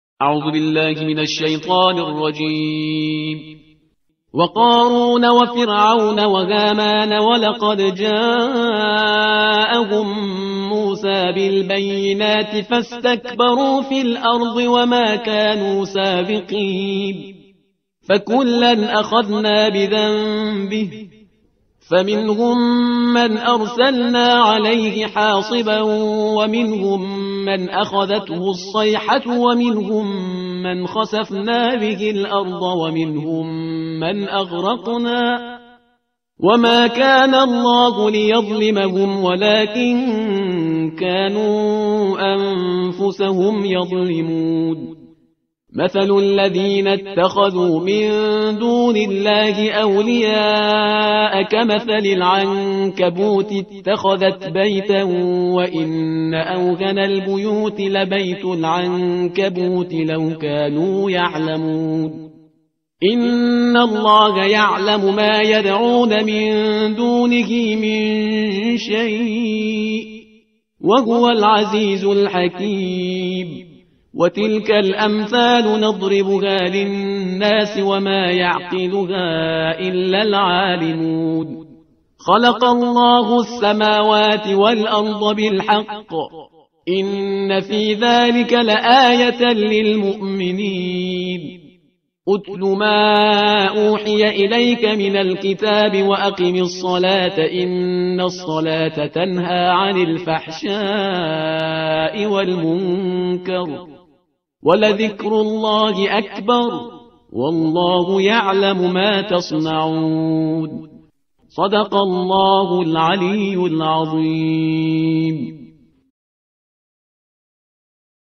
ترتیل صفحه 401 قرآن با صدای شهریار پرهیزگار